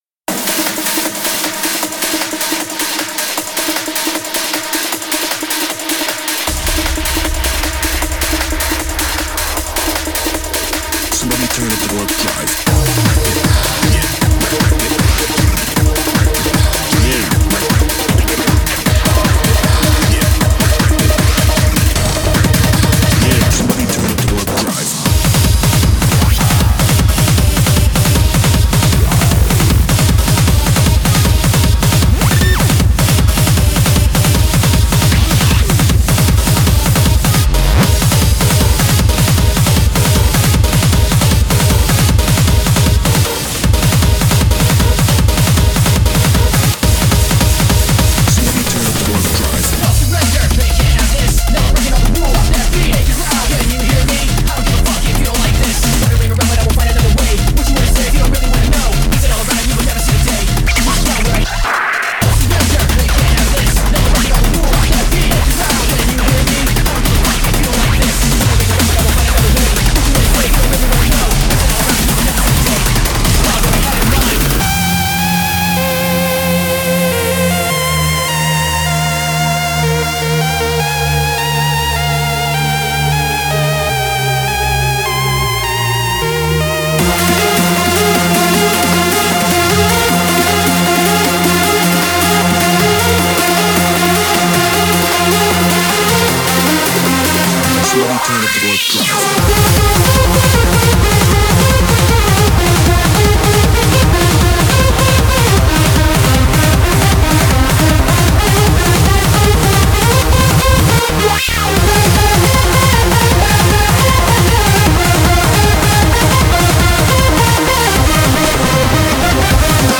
BPM155
The offbeat rhythms made it a fun challenge to step, too.